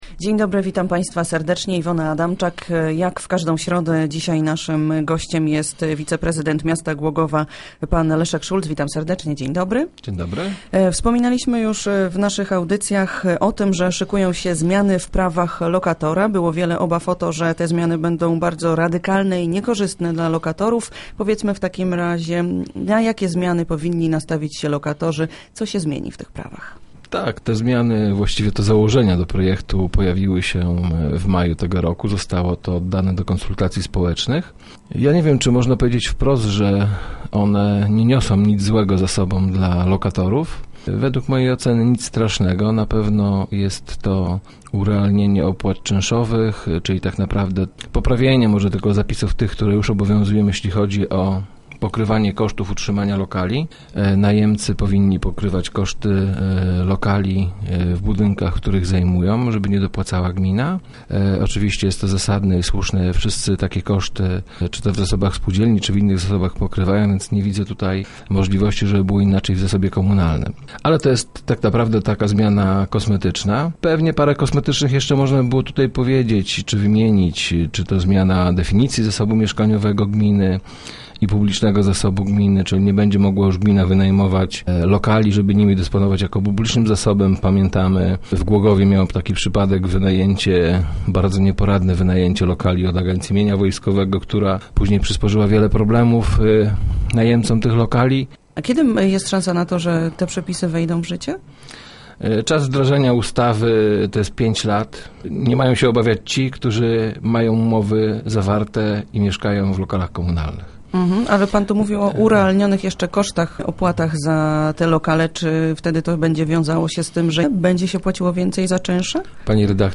- Dotyczą na przykład kosztów najmu czy zasad przyznawania lokali - informuje Leszek Szulc, zastępca prezydenta Głogowa, który był gościem Rozmów Elki.